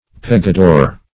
Search Result for " pegador" : The Collaborative International Dictionary of English v.0.48: Pegador \Pe`ga*dor"\, n. [Sp., a sticker.]